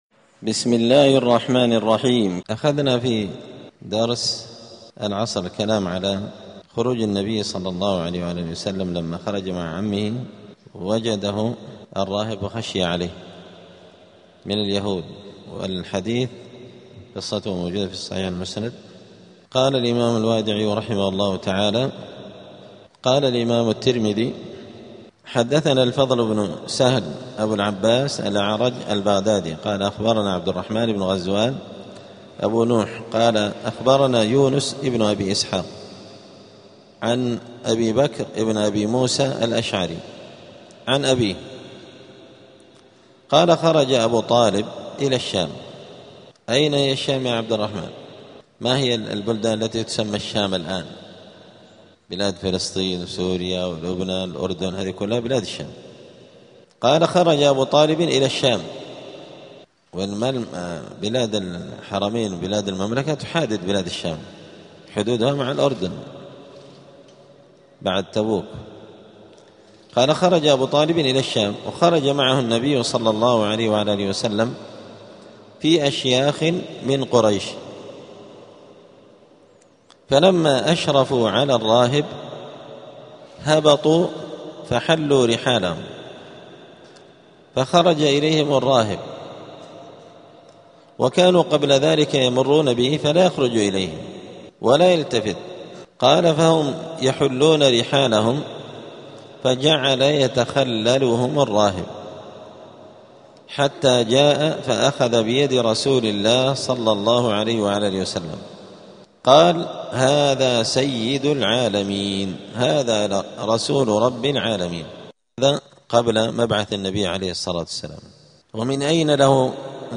*الدرس الثالث عشر (13) {فصل ختان النبي صلى الله عليه وسلم}.*
الجمعة 20 رجب 1447 هــــ | الدروس، دروس الآداب، زاد المعاد في هدي خير العباد لابن القيم رحمه الله | شارك بتعليقك | 5 المشاهدات